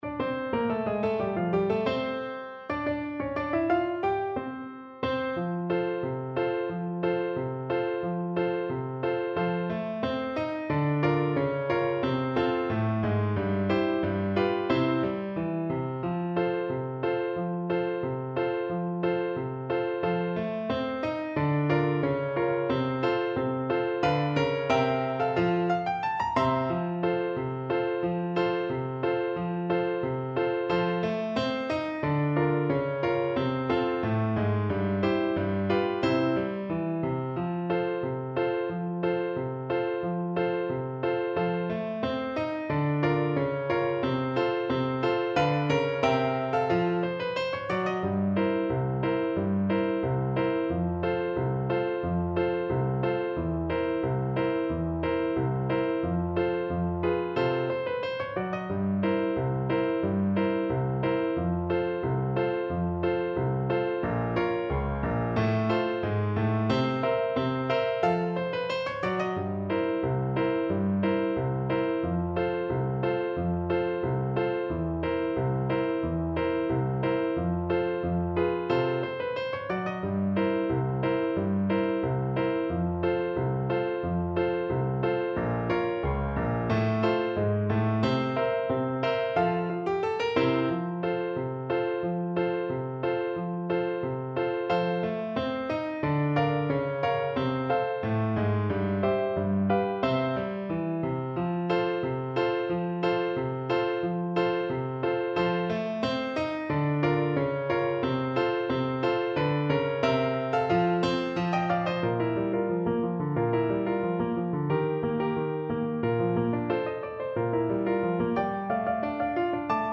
Play (or use space bar on your keyboard) Pause Music Playalong - Piano Accompaniment Playalong Band Accompaniment not yet available transpose reset tempo print settings full screen
F major (Sounding Pitch) C major (French Horn in F) (View more F major Music for French Horn )
Slow march tempo Slow March tempo. = 90
2/4 (View more 2/4 Music)